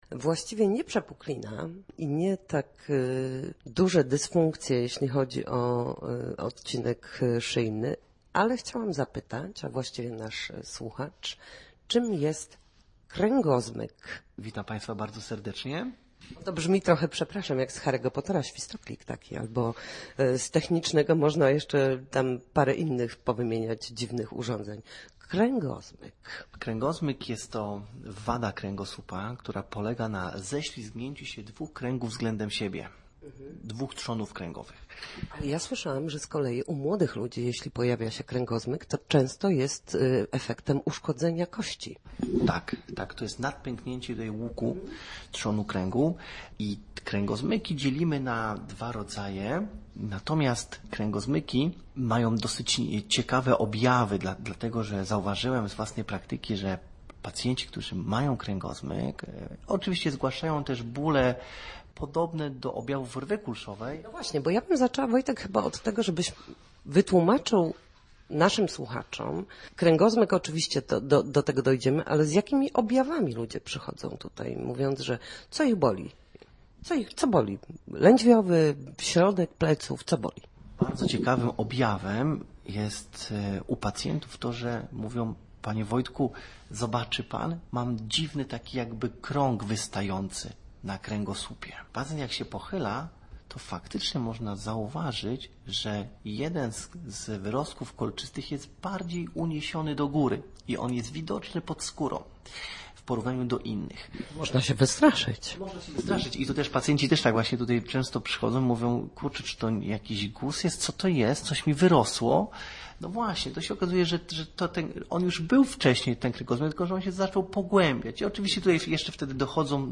W każdą środę w popołudniowym Studiu Słupsk Radia Gdańsk dyskutujemy o tym, jak wrócić do formy po chorobach i urazach.